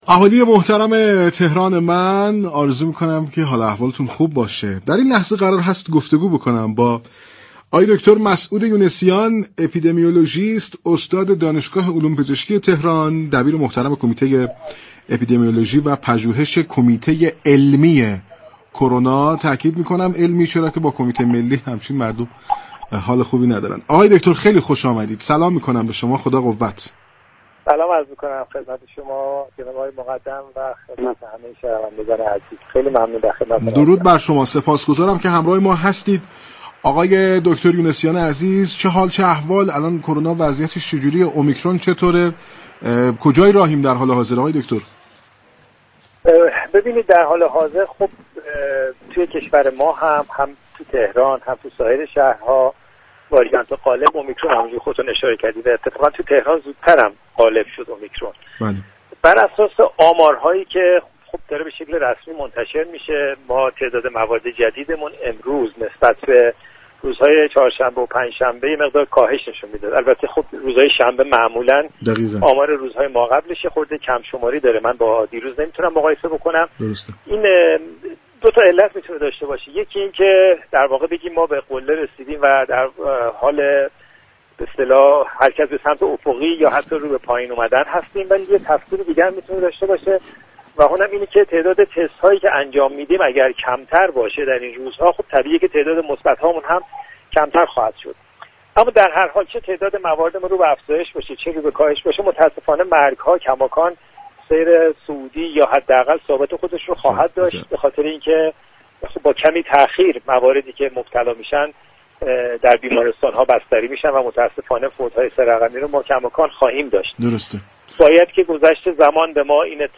در گفتگو با برنامه تهران من رادیو تهران